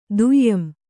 ♪ duyyam